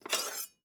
SFX_Cooking_Knife_PickUp_01.wav